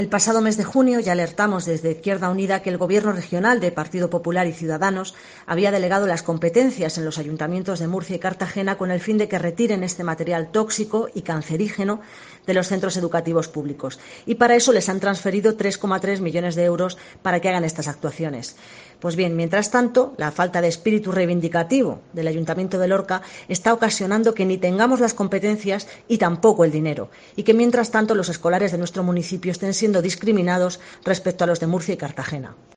Gloria Martín, edil de IU Verdes sobre amianto